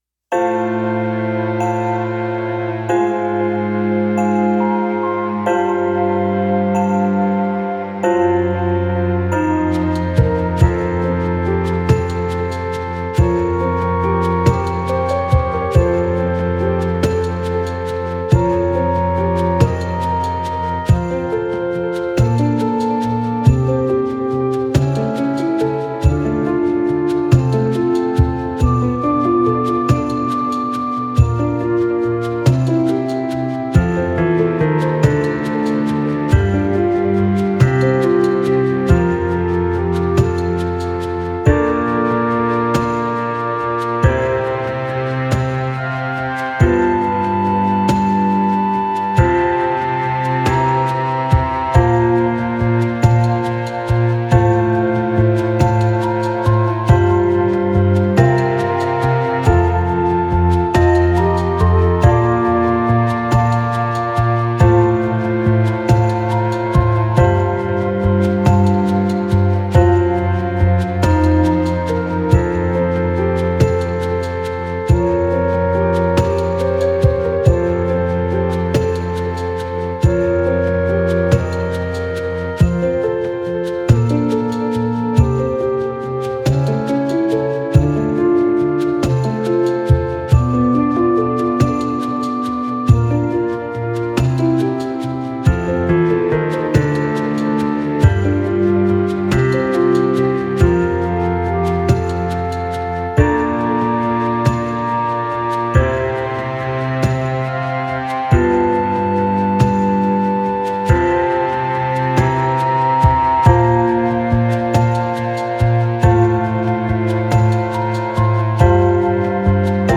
【Instrumental】 mp3 DL ♪